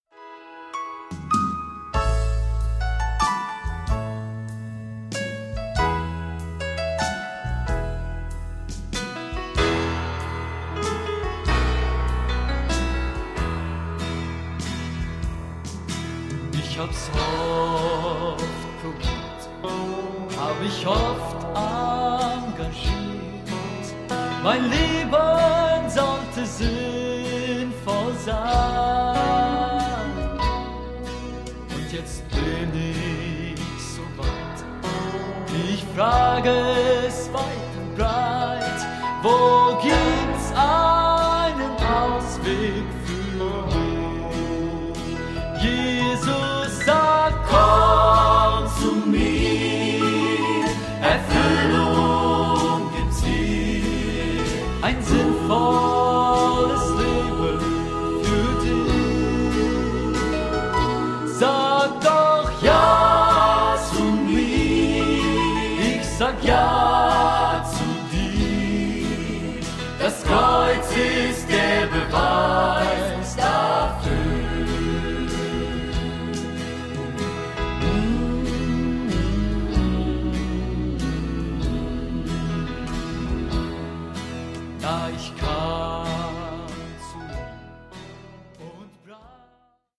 Harmonisch, von Herzen kommend und gefühlvoll.
Chormusik/Evangeliumslieder